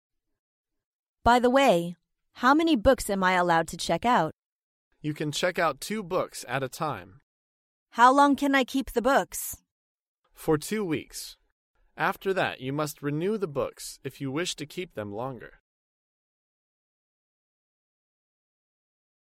在线英语听力室高频英语口语对话 第462期:出借图书咨询的听力文件下载,《高频英语口语对话》栏目包含了日常生活中经常使用的英语情景对话，是学习英语口语，能够帮助英语爱好者在听英语对话的过程中，积累英语口语习语知识，提高英语听说水平，并通过栏目中的中英文字幕和音频MP3文件，提高英语语感。